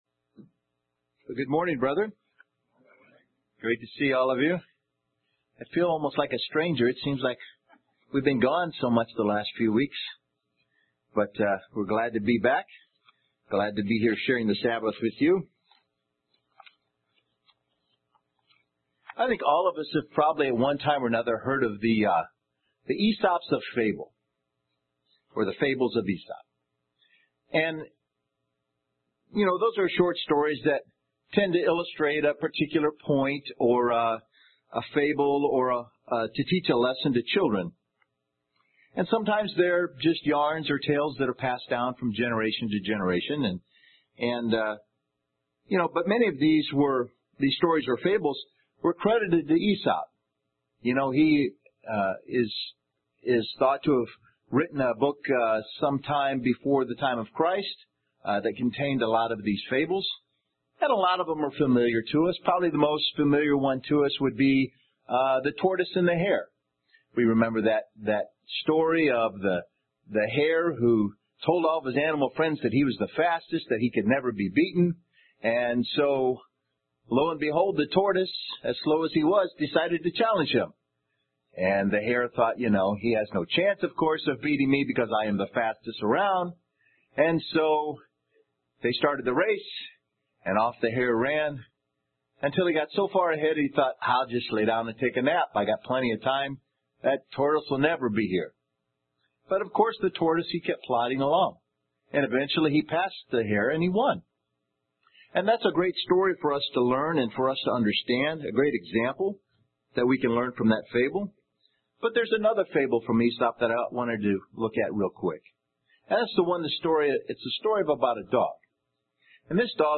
UCG Sermon Studying the bible?
Given in Murfreesboro, TN